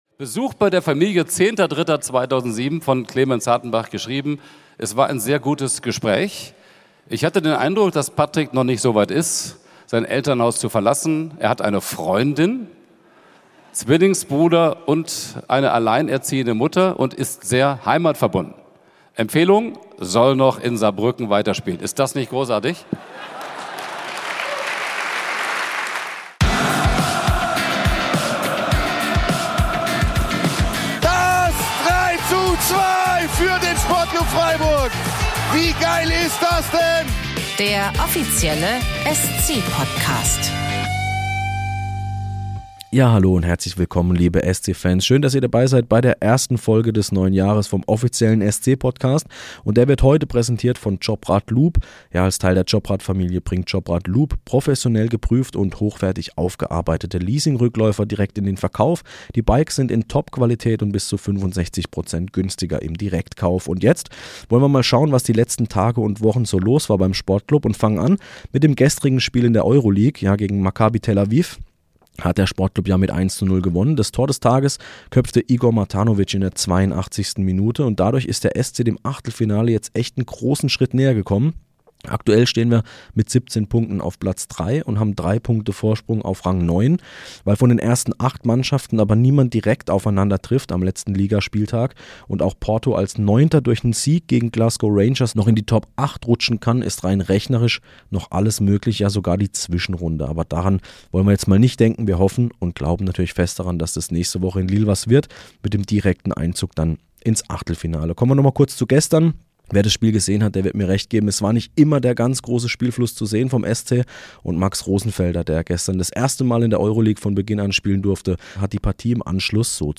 Beschreibung vor 2 Monaten Wie schon in den vergangenen Jahren fand auch in diesem Jahr wieder der Neujahrsempfang des SC Freiburg statt und auch dieses Mal unterhielt sich Tom Bartels vor rund 500 Gästen im Europa-Park mit Funktionären und Spielern des SC Freiburg.